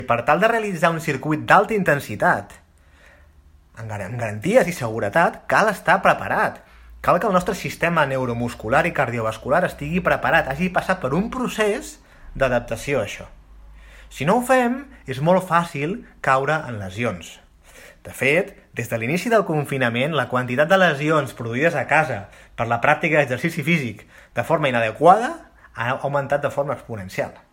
han estat protagonistes d’un reportatge radiofònic a Girona FM